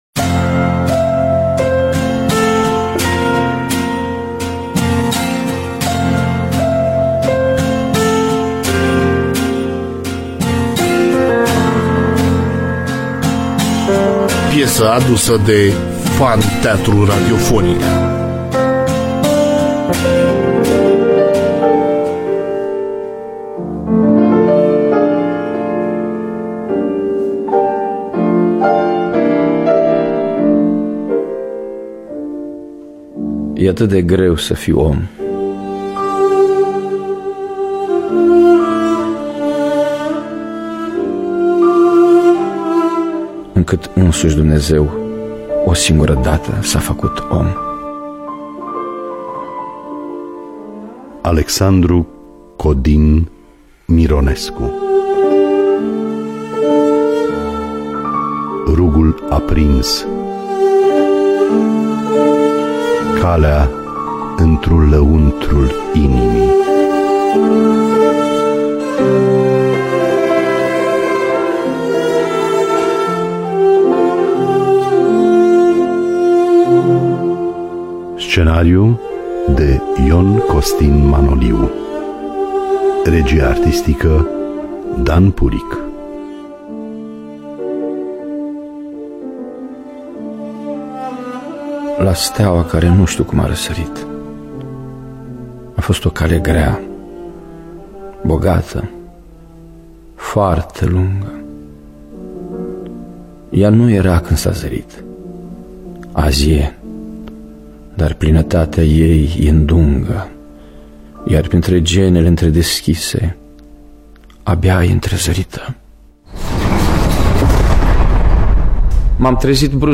Rugul Aprins. Calea întru lăuntrul inimii. Scenariu radiofonic
Muzica originală şi regia muzicală